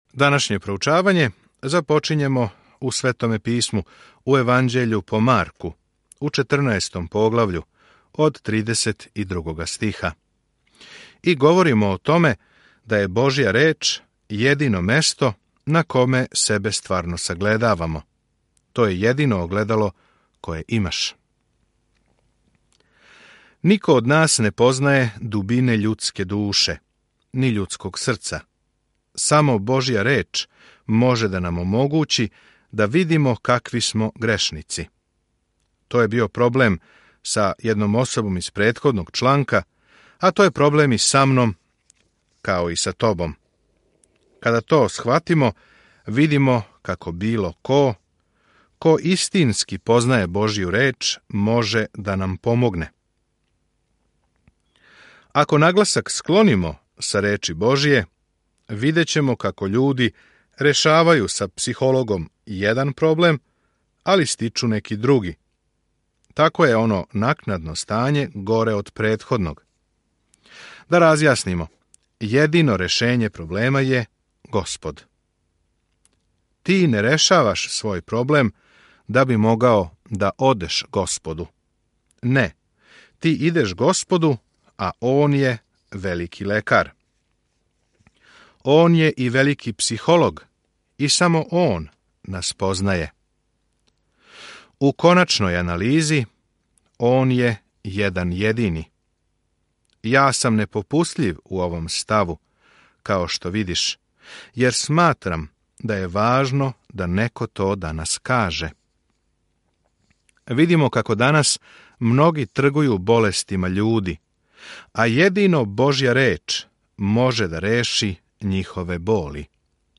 Sveto Pismo Marko 14:32-62 Dan 21 Započni ovaj plan Dan 23 O ovom planu Марково краће јеванђеље описује земаљску службу Исуса Христа као напаћеног Слуге и Сина човечијег. Свакодневно путујте кроз Марка док слушате аудио студију и читате одабране стихове из Божје речи.